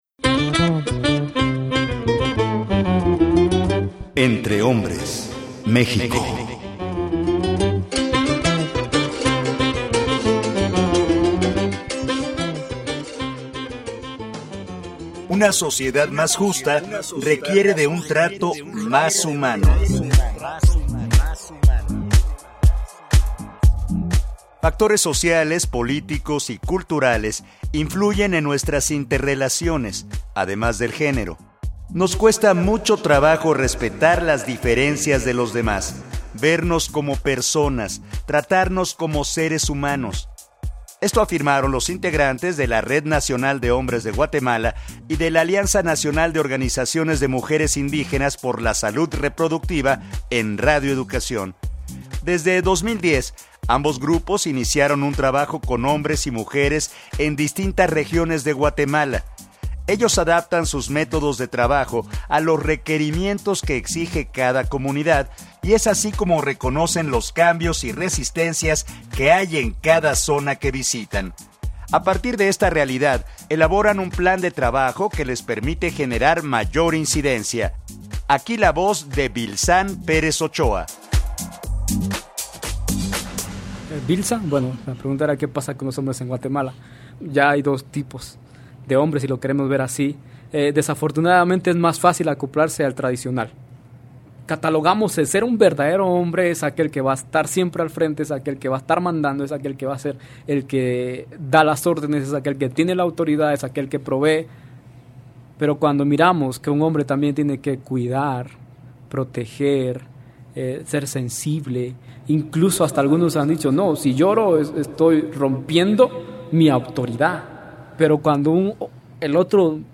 Nos cuesta mucho trabajo respetar las diferencias de los demás… vernos como personas, tratarnos como seres humanos, esto afirmaron los integrantes de la Red Nacional de hombres de Guatemala y de la Alianza Nacional de Organizaciones de Mujeres Indígenas por la Salud Reproductiva en Radio Educación.